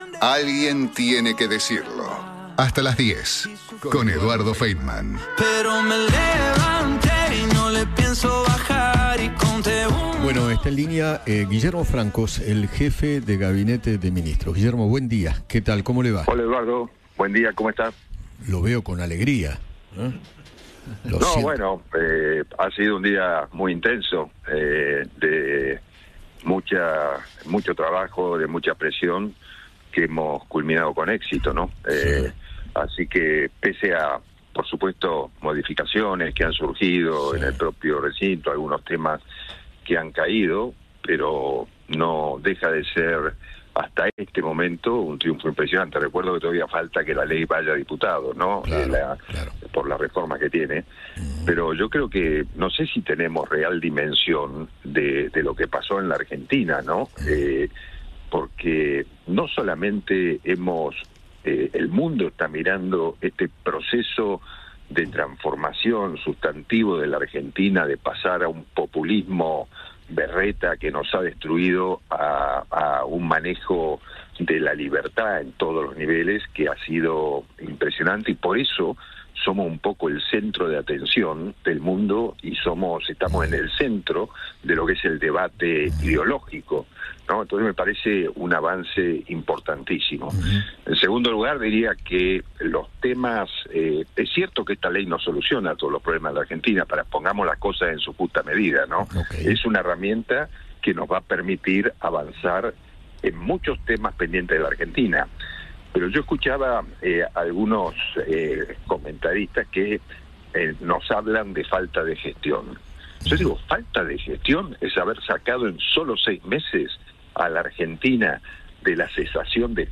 Guillermo Francos, jefe de Gabinete, dialogó con Eduardo Feinmann tras la aprobación de la Ley Bases en el Senado y adelantó cuándo y dónde podría firmarse el Pacto de Mayo.